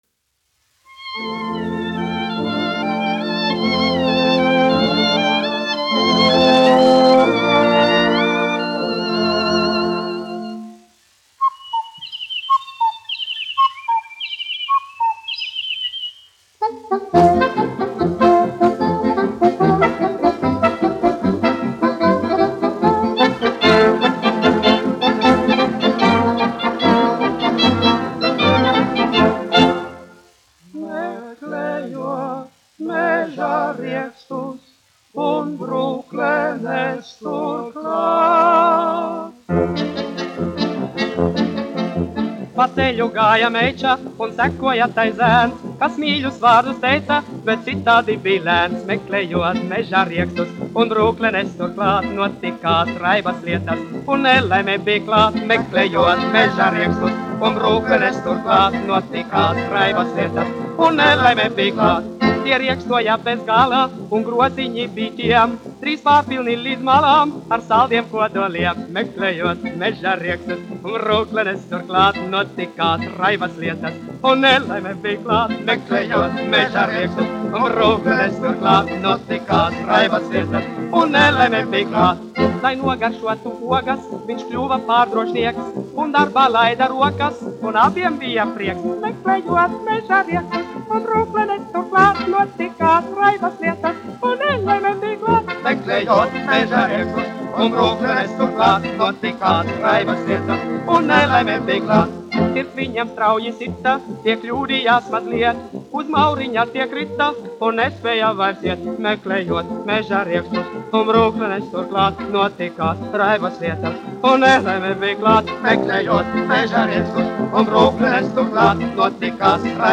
dziedātājs
1 skpl. : analogs, 78 apgr/min, mono ; 25 cm
Fokstroti
Populārā mūzika
Skaņuplate